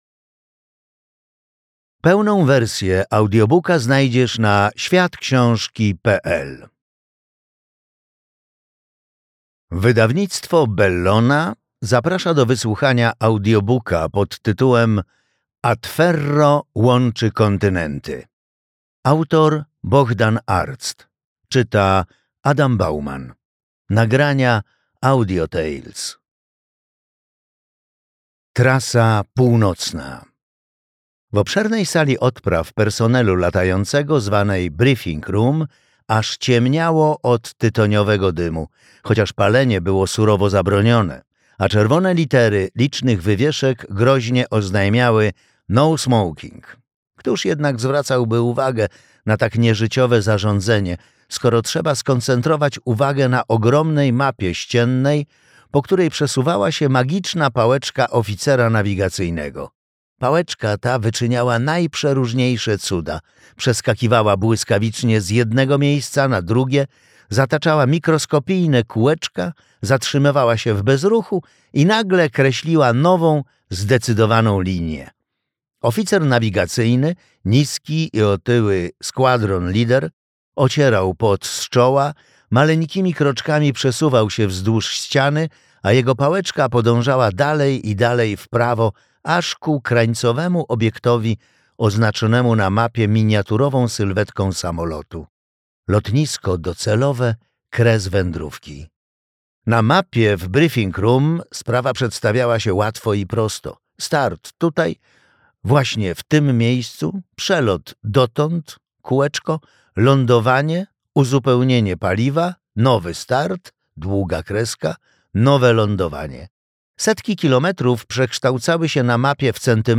Atferro łączy kontynenty - audiobook